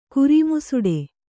♪ kuri musuḍe